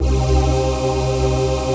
VOICE-PAD 11
VOICEPAD11-LR.wav